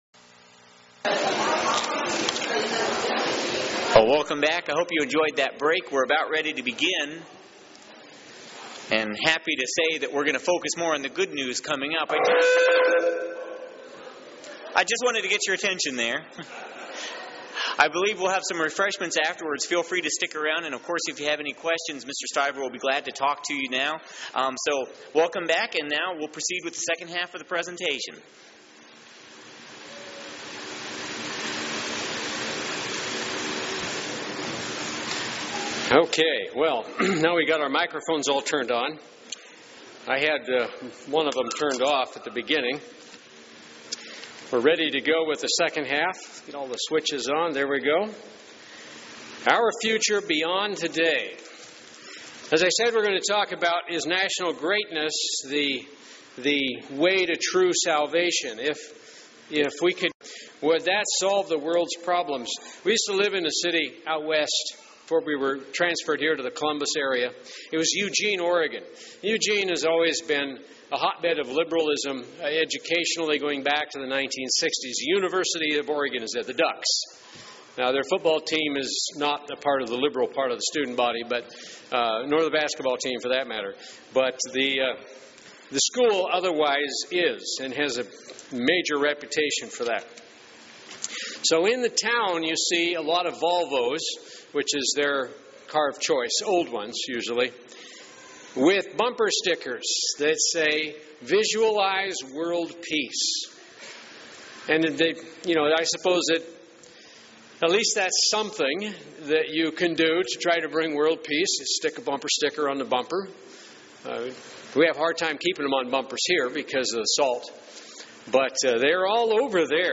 This Public Bible Lecture contrasts current national issues and prophesied troubles with with the coming Kingdom of God.